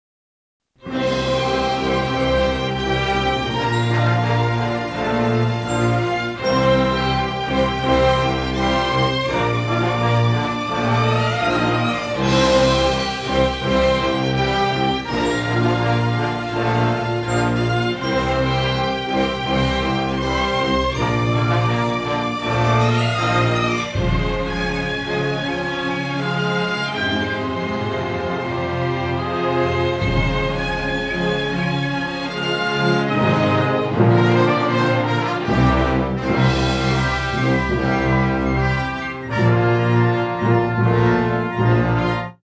I had stuff played by real symphony orchestra in college, before DBZ...so yeah.
Before DBZ, live orchestra (sight read by students, no rehearsal)
HeroicTheme.mp3